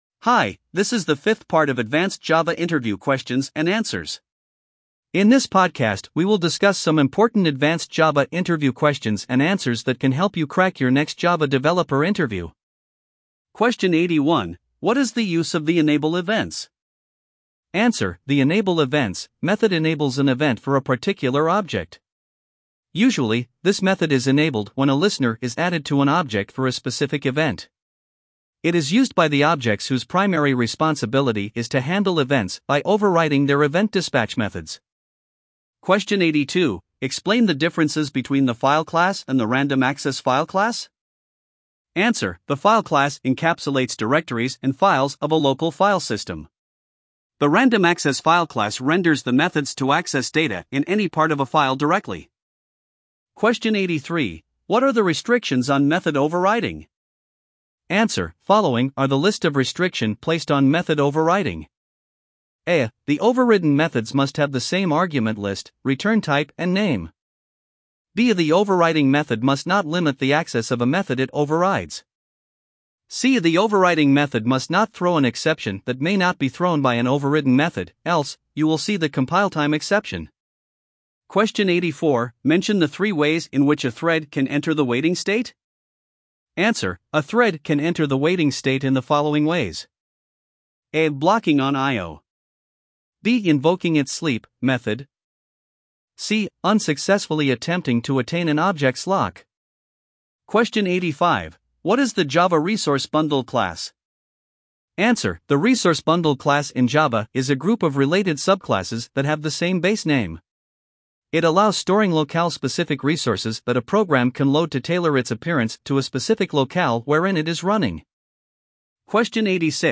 LISTEN TO THE ADVANCED JAVA FAQs LIKE AN AUDIOBOOK